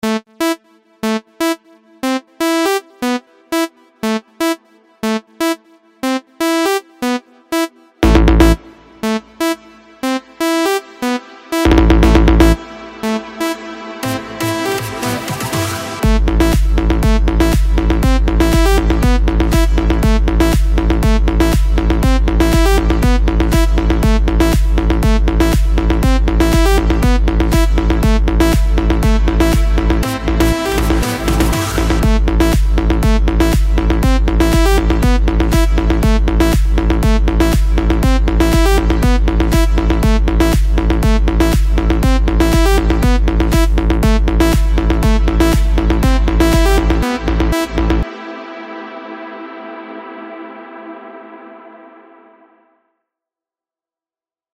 וואו זה ממש ממש יפה מתאים לריקודים מגניבים כאלה...
הטראק בנוי על לופ מוכר (לא זוכר עכשיו איפה שמעתי אותו בעבר)
הוספתי עליו קצת התפתחות בבס, שיחקתי עם האפקטים, ויצא בסוף משהו נחמד